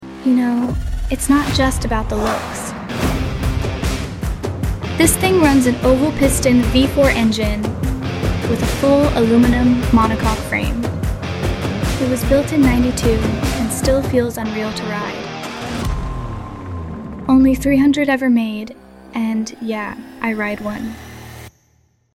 Honda NR750 sound effects free download